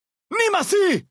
Dead Horses pidgin audio samples Du kannst diese Datei nicht überschreiben.
Grenade!.ogg